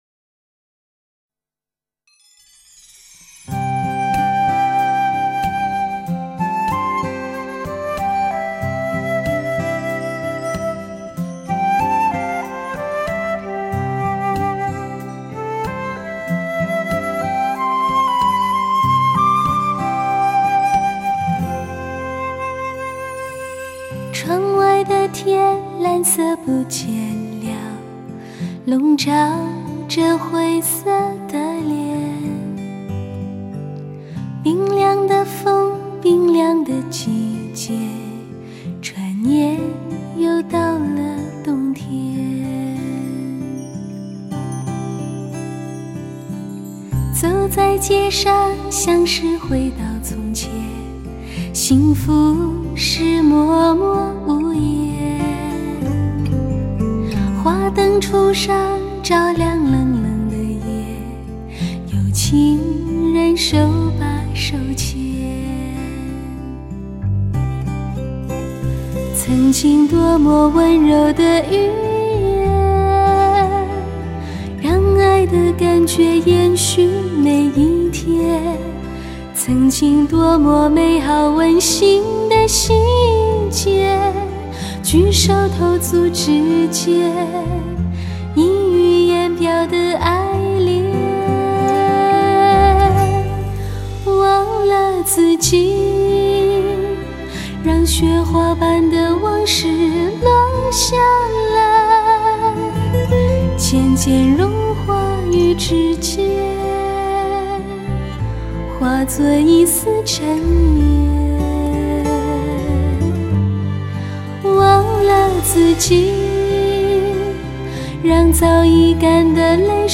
乐器的分隔度、定位良好，弦乐群华丽高贵，钢琴的形态和位置浮现，琴键的触音粒粒可闻。
旋律优美、歌词诗情画意、隽永动人，使人勾起以往温馨的回忆。
甜美的歌聲.